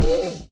Sound / Minecraft / mob / horse / zombie / hit2.ogg